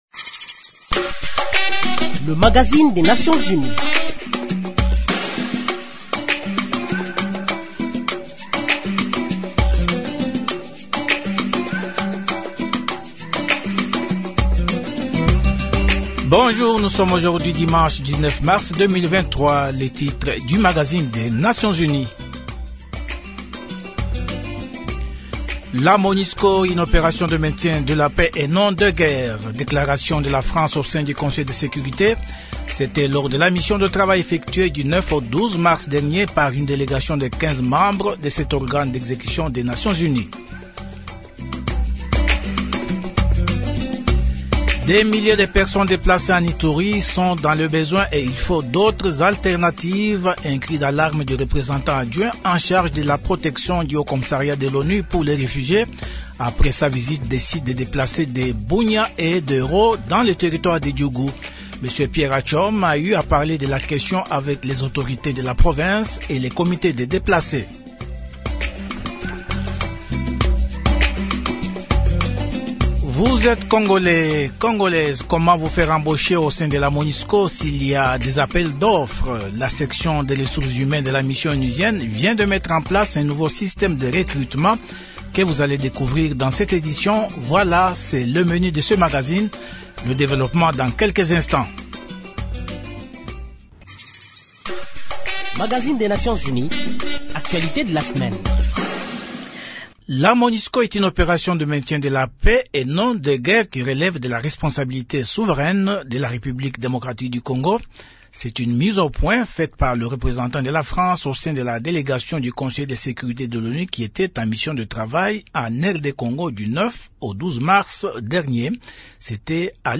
Un système efficace, conçu pour accélérer l’embauche du personnel national congolais. Dans un entretien